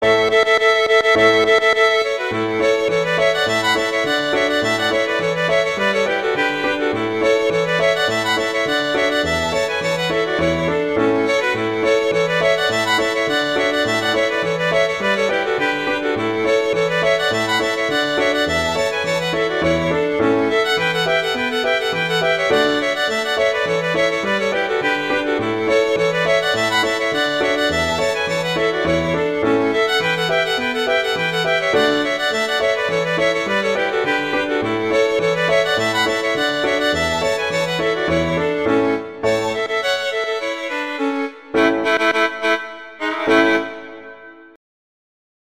arrangements for violin and piano
traditional, children